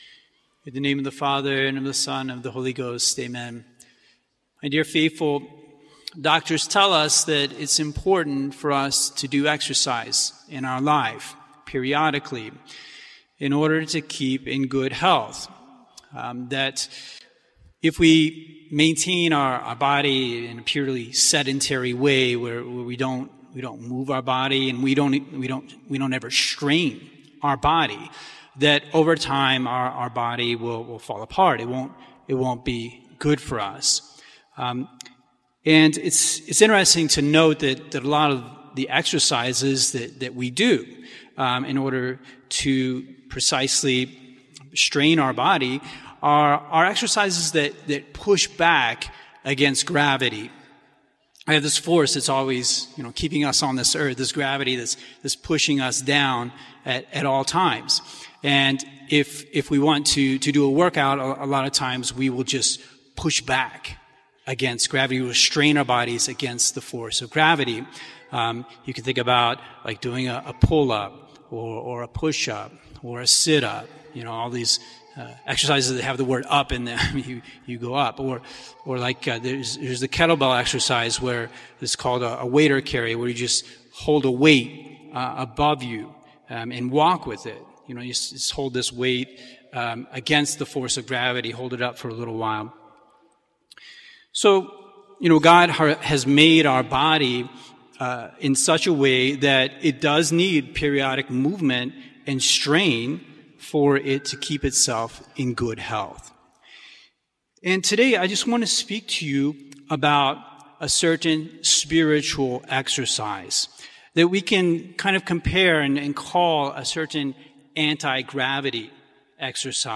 The Importance of Doing Spiritual Exercise, Sermon